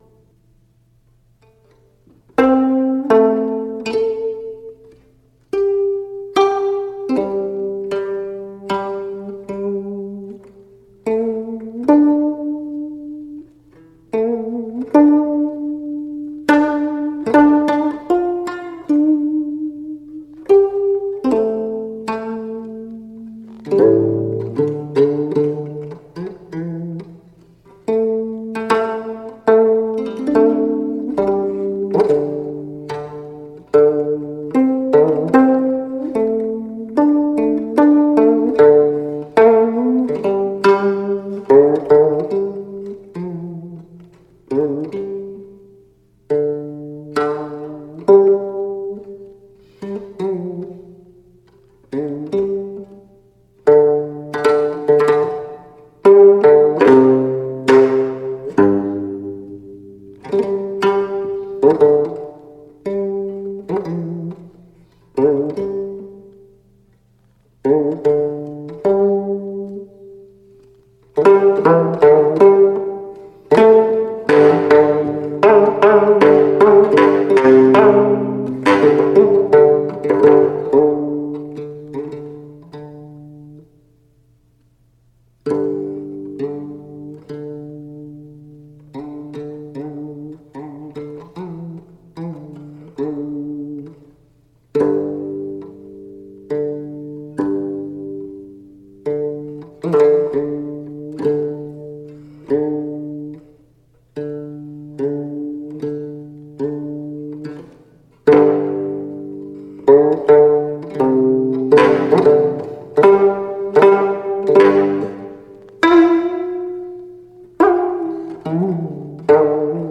カヤグム（伽耶琴）とコムンゴ（玄琴）の音源集！